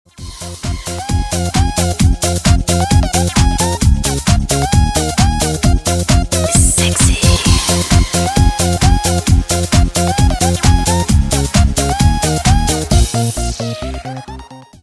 • Качество: 128, Stereo
dance
Eurodance
дискотека 90-х